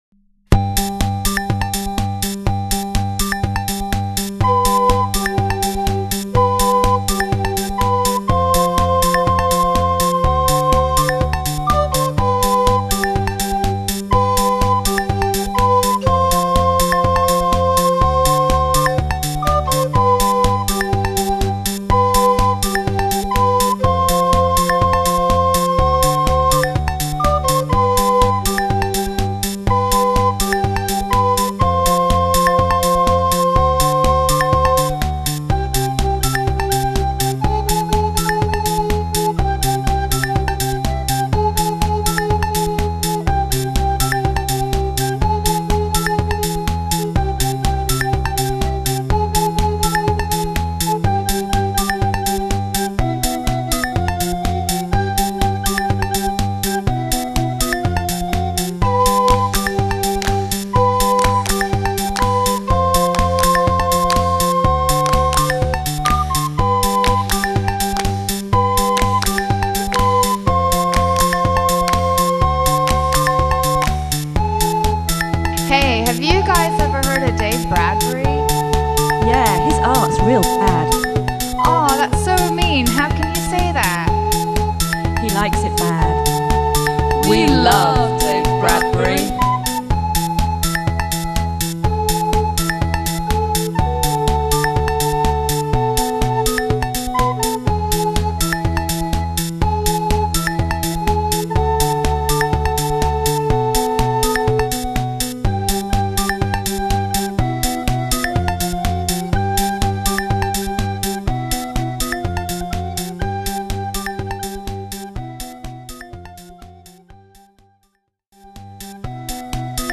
a recorder band with a great future.